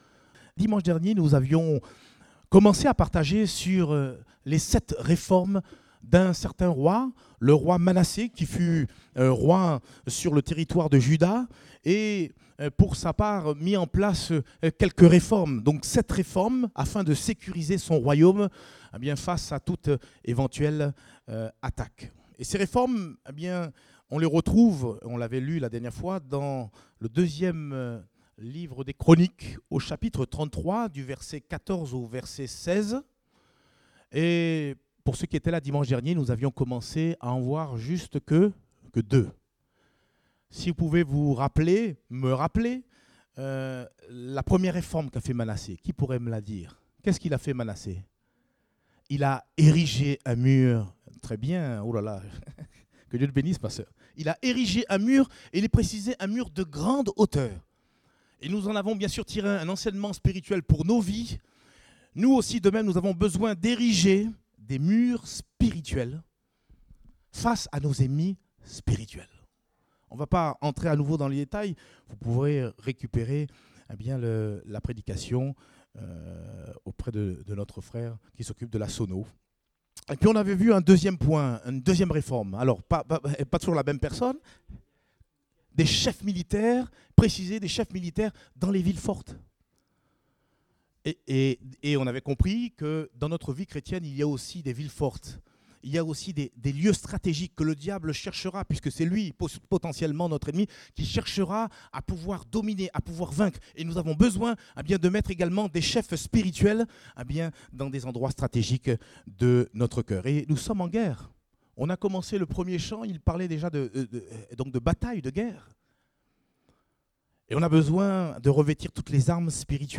Date : 22 juillet 2018 (Culte Dominical)